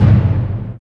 Index of /90_sSampleCDs/Roland LCDP03 Orchestral Perc/PRC_Orch Bs Drum/PRC_Grongkas